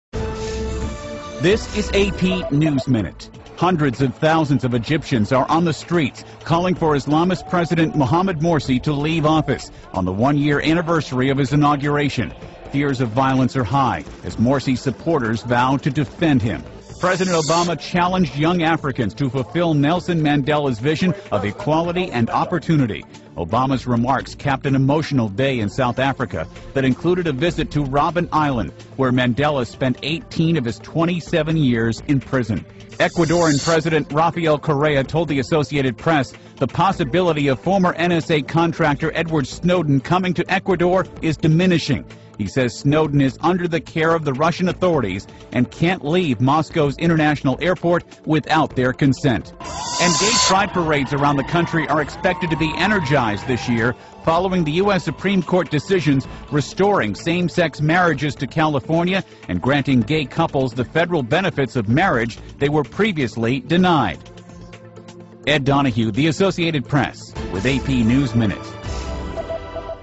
在线英语听力室美联社新闻一分钟 AP 2013-07-04的听力文件下载,美联社新闻一分钟2013,英语听力,英语新闻,英语MP3 由美联社编辑的一分钟国际电视新闻，报道每天发生的重大国际事件。电视新闻片长一分钟，一般包括五个小段，简明扼要，语言规范，便于大家快速了解世界大事。